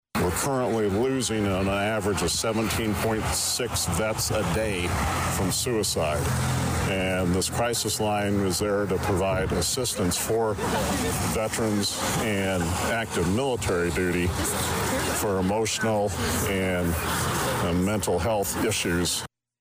It began at 11 AM, on Main Street in front of VA Illiana Health Care; with a crowd of already of 70, and the numbers kept increasing during Saturday morning’s HANDS OFF rally in Danville; one of many across the country that were put together to protest the actions of Elon Musk and the Trump Administration.